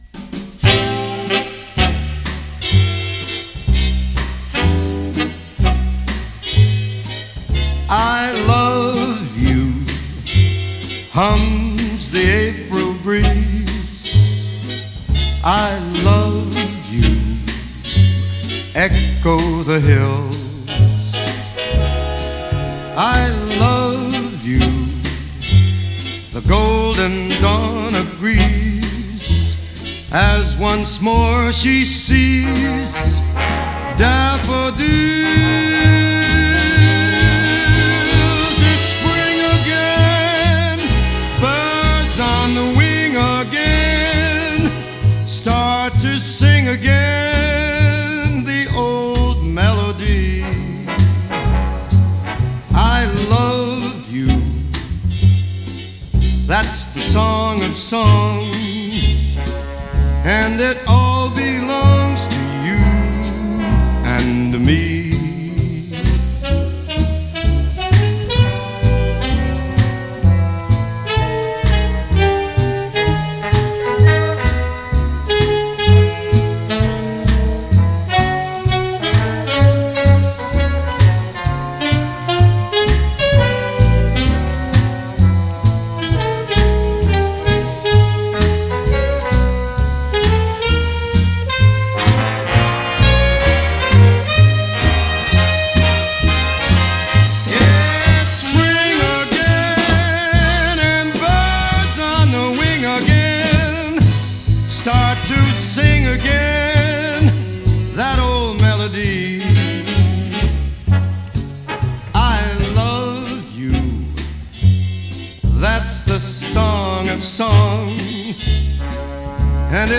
Genre: Pop, Jazz, Soundtrack, Instrumental, Divers, Italian